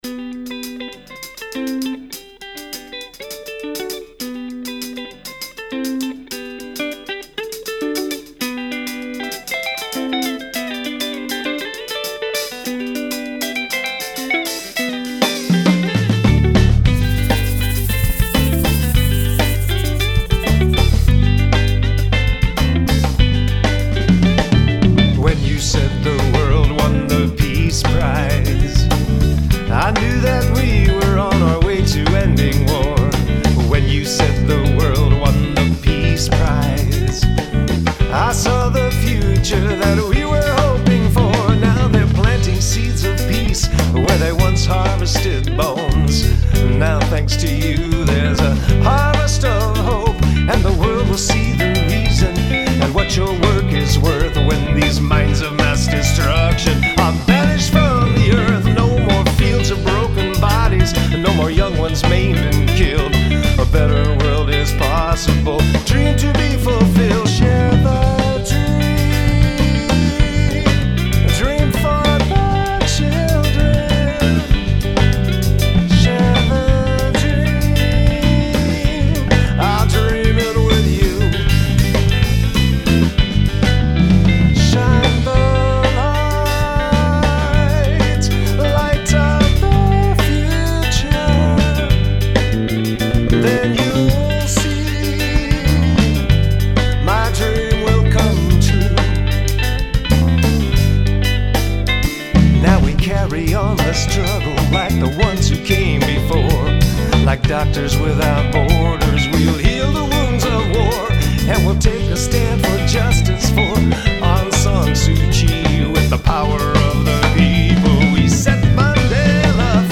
drums
Bass
sax
voice
An early version had a line about Rigoberta Menchu (1992 winner) and Desmond Tutu (1984) but the phrasing was difficult in the syncopated 7/8 groove.
(Incidentally, Peace Prize uses the same chord progression ( G - Ami - G - D ) as a similar song (in 6/8), “Medecins sans Frontieres,” co-written in 1996 with poet Jonathan Stevens.